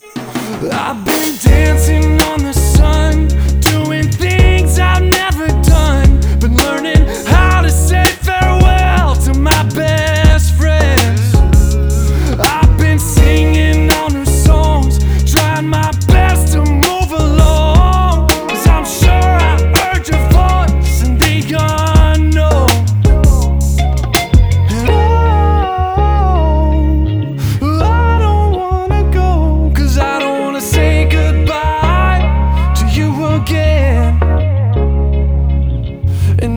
Pop-Christian - On The Line_BZMjGs6xp9.wav